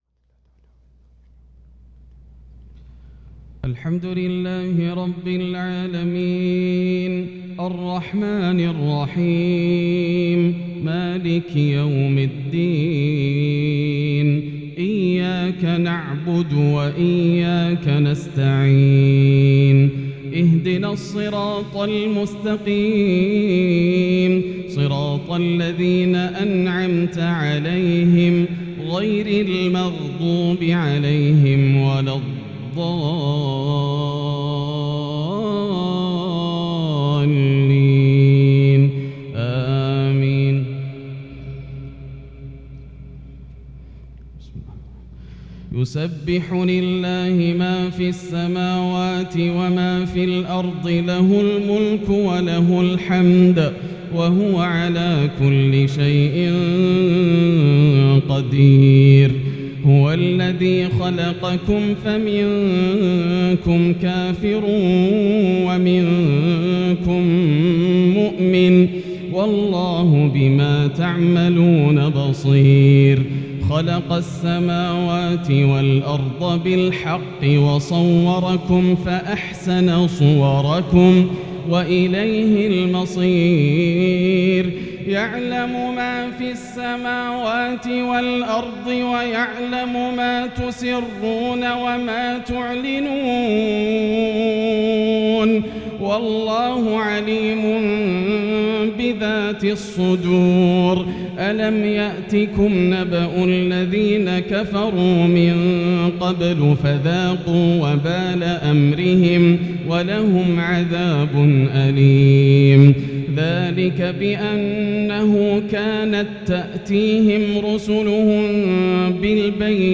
عشاء الخميس 9-2-1443هـ سورة التغابن | lsha Prayer from Surat At-Taghabun 16/9/2021 > 1443 🕋 > الفروض - تلاوات الحرمين